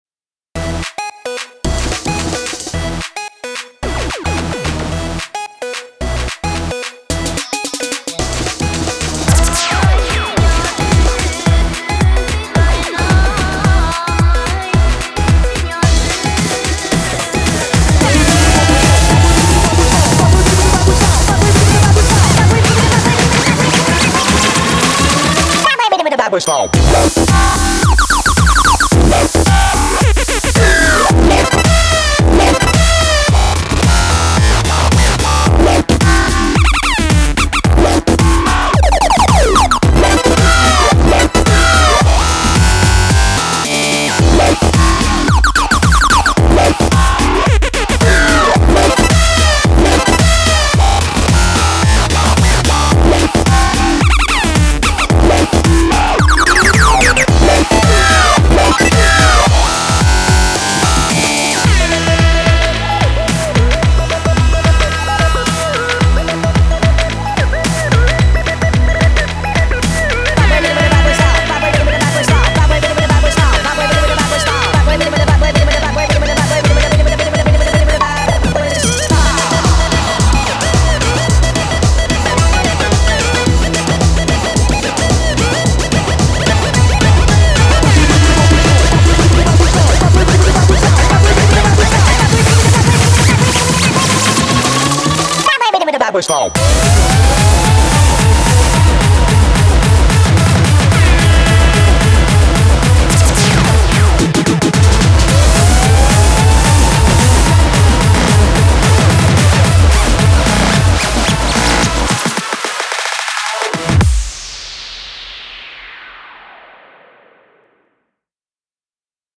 BPM110-220
Audio QualityPerfect (High Quality)
Beware of the speed changes!